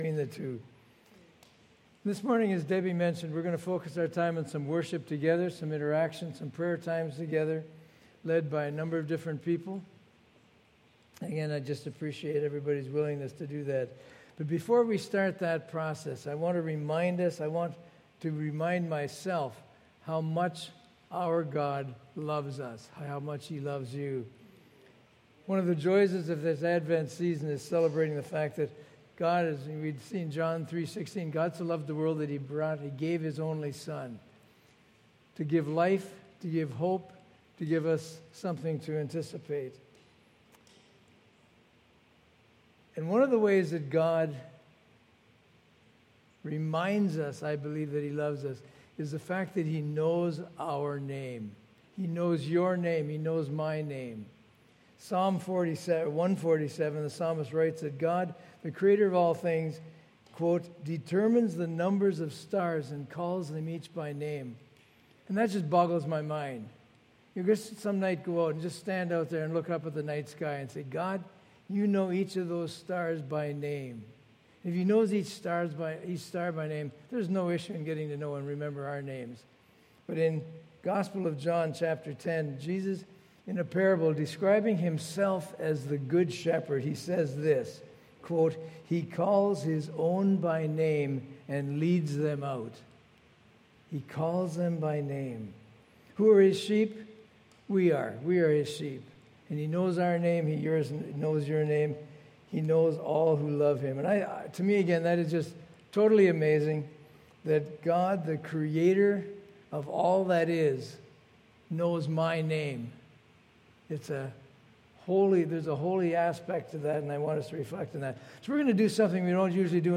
The Gathering Church - A Service of Prayer
Join us this Sunday as we worship God through songs, scripture, story and prayers.